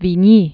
(vēn-yē), Comte Alfred Victor de 1797-1863.